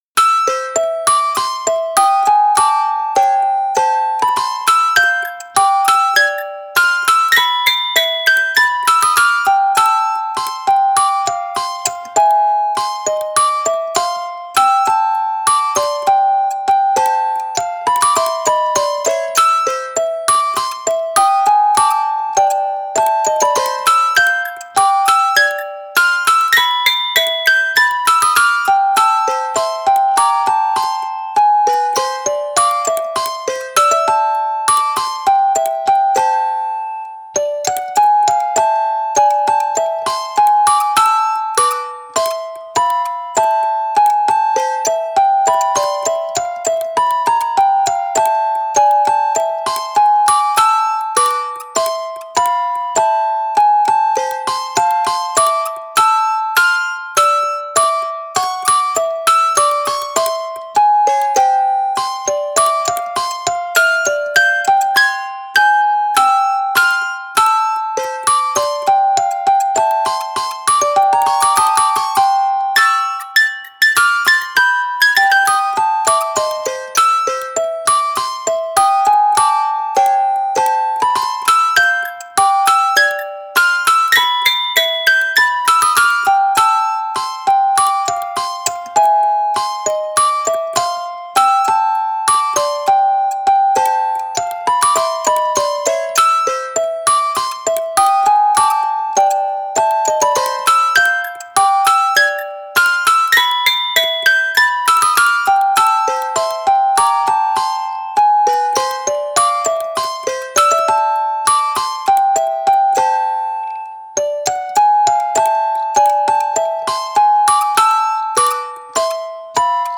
ゆったりした曲です。
ちょっと不気味さもあるんです
タグ ゆったり　　　オルゴール